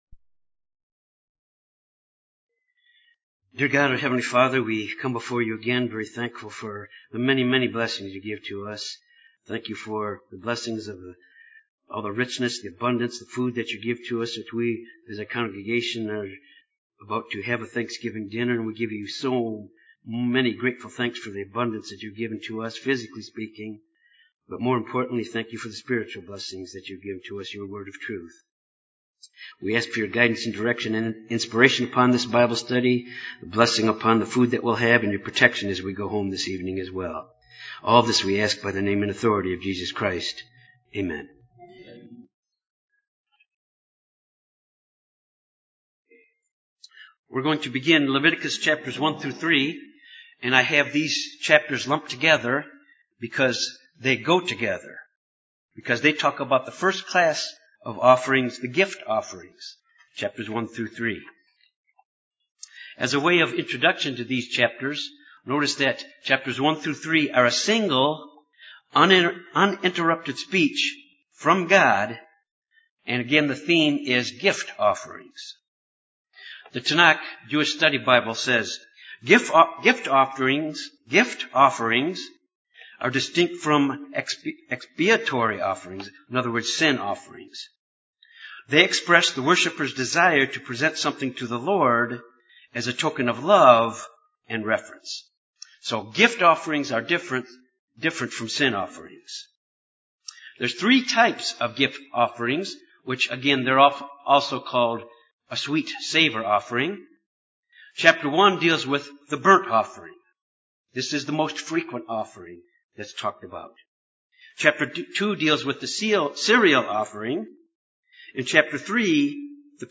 This Bible Study deals with God’s instructions to the Israelites concerning burnt offerings, grain offerings and peace offerings.
Given in Jonesboro, AR Little Rock, AR Memphis, TN